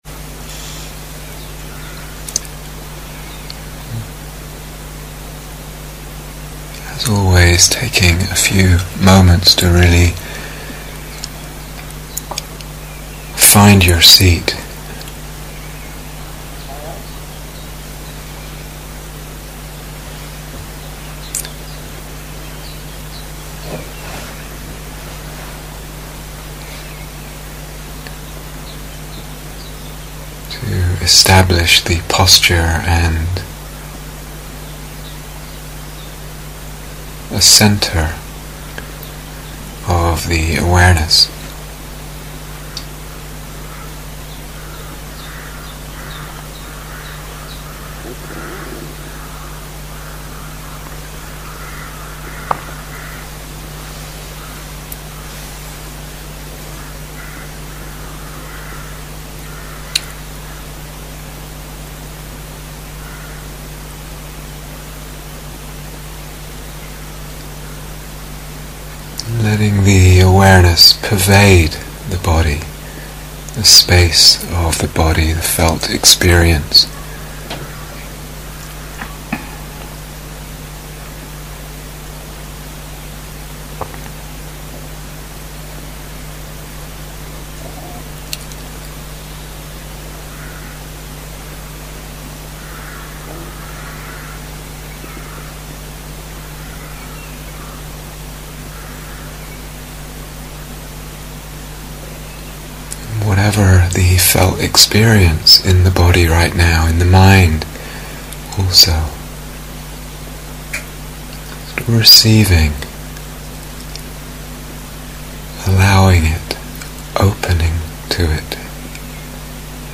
Guided Meditation - The Play of Appearances